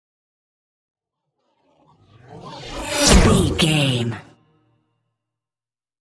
Pass by fast speed engine
Sound Effects
pass by
car
vehicle